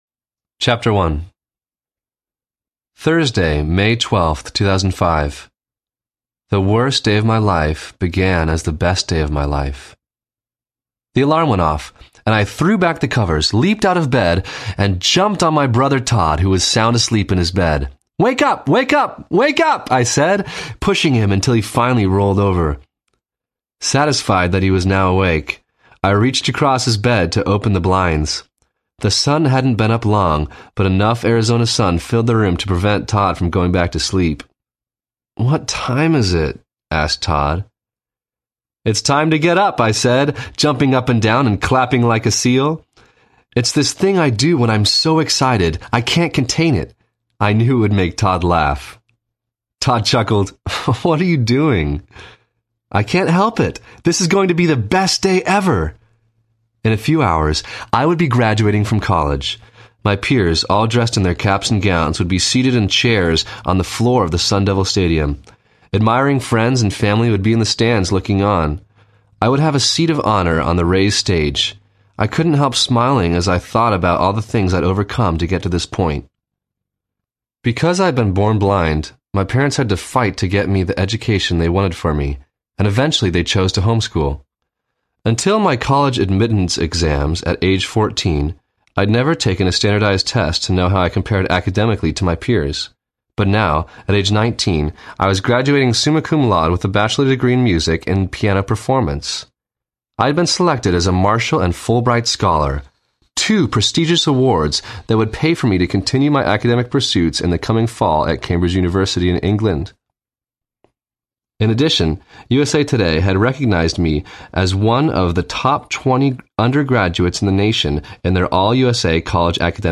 By Faith, Not By Sight Audiobook
Narrator